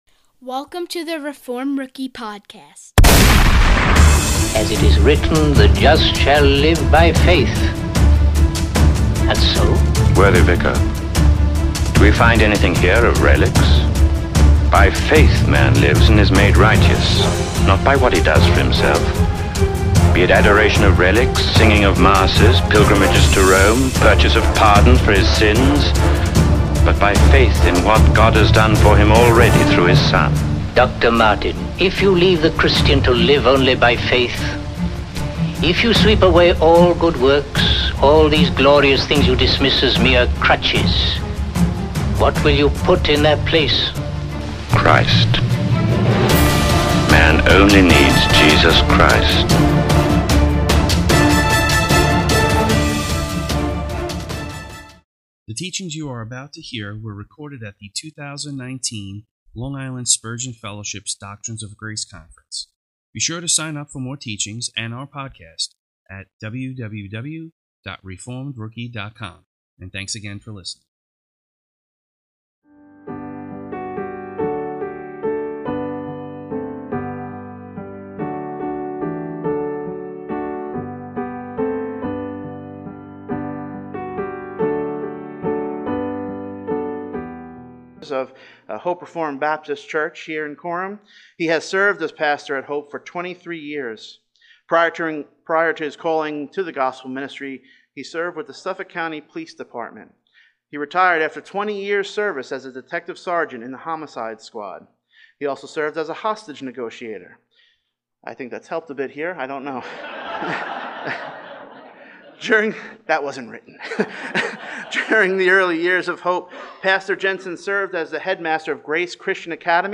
Recorded at the LI Spurgeon Fellowship: Doctrines of Grace Conference 2019